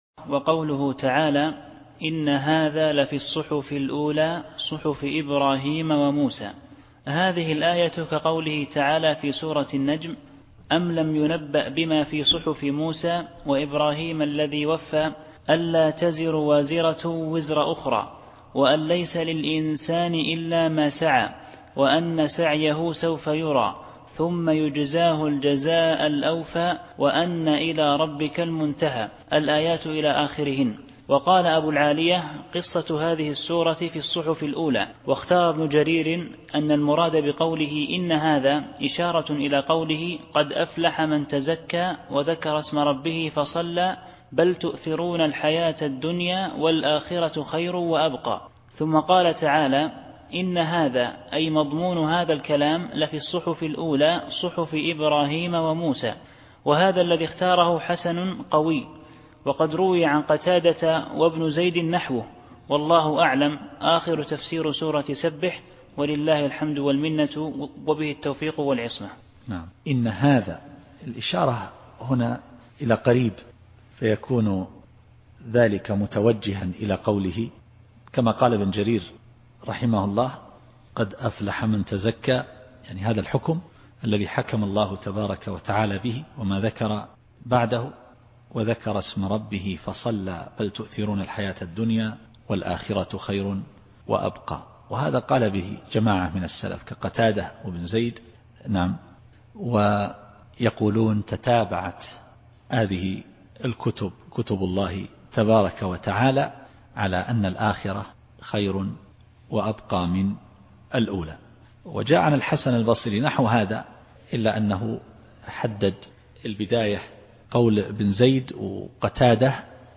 التفسير الصوتي [الأعلى / 18]